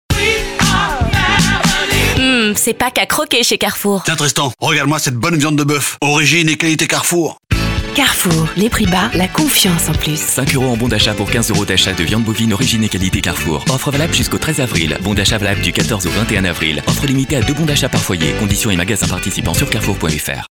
Sa diction irréprochable, sa rigueur technique et sa capacité à garder une intention malgré la contrainte de temps font toute la différence.
3. CARREFOUR rapide, clair